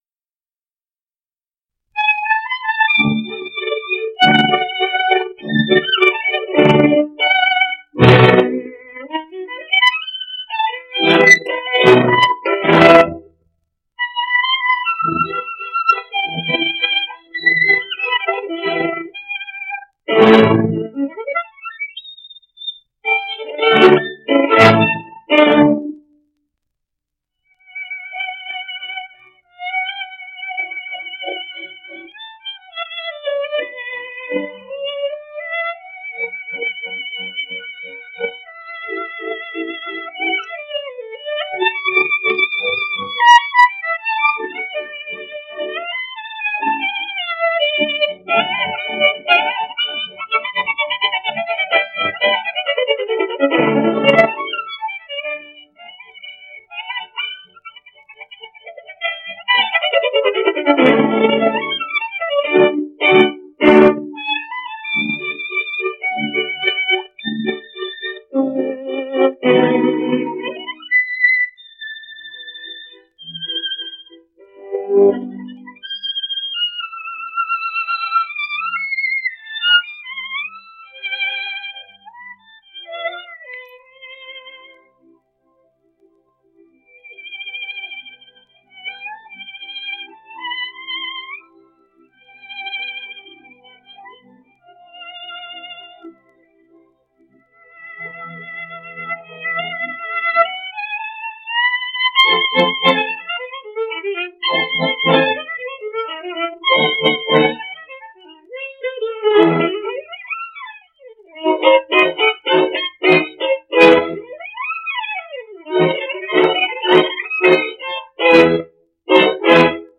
Concerto No. 1 in D major, Op. 6. 1st movement (part 2) (sonido mejorado)